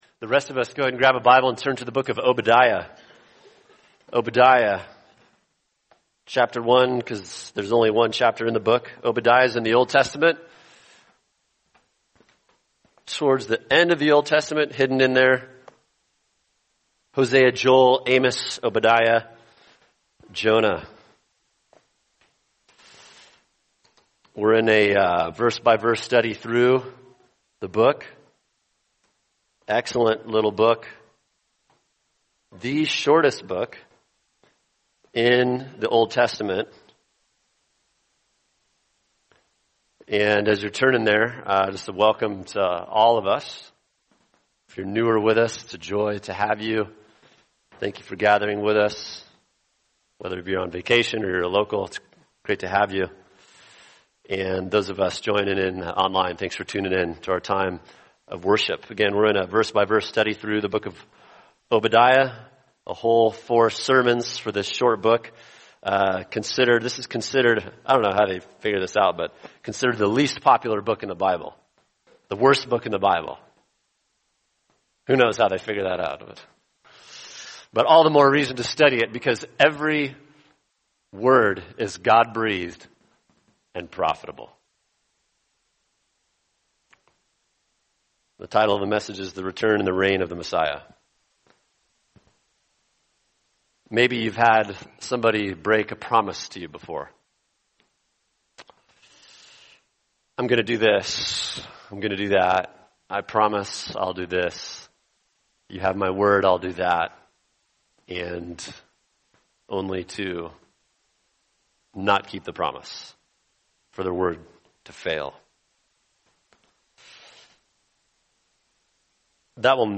[sermon] Obadiah 1:17-21 The Messiah’s Return and Reign | Cornerstone Church - Jackson Hole